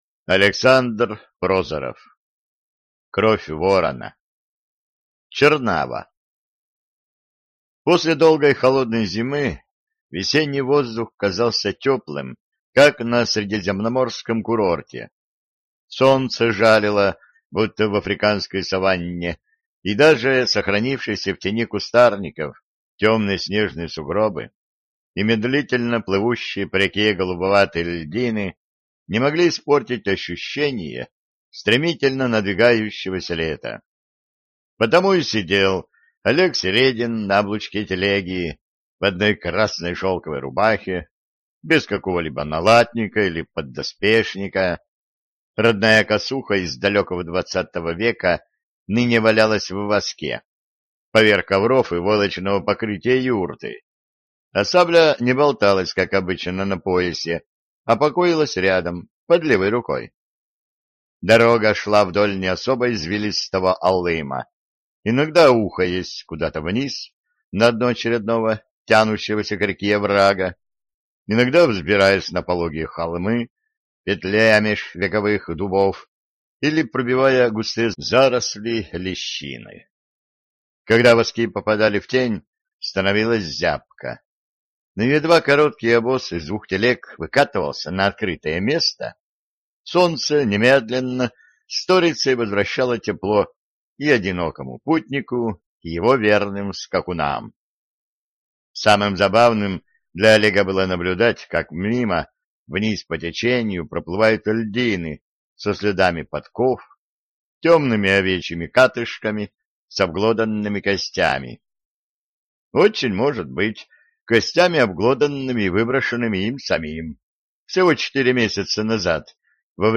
Аудиокнига Кровь ворона | Библиотека аудиокниг